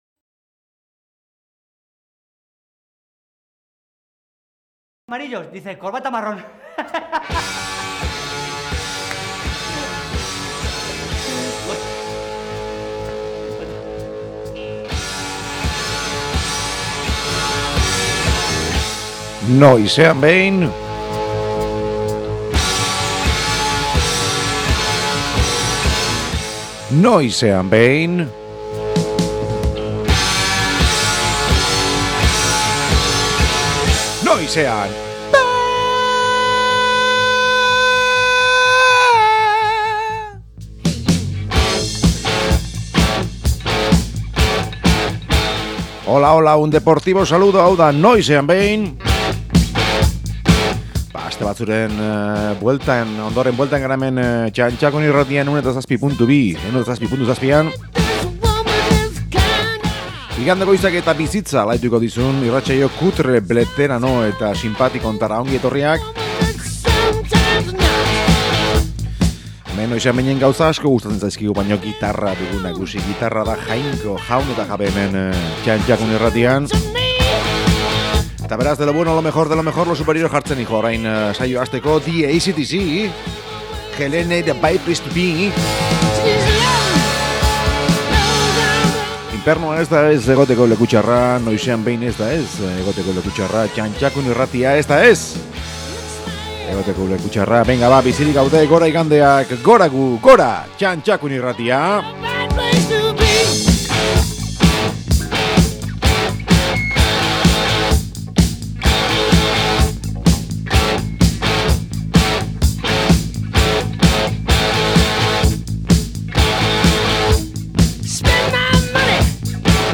Blues pixka bat, rock pixka bat askoz gehiago eta gero galtzak fuera, heavy metallllll!!!!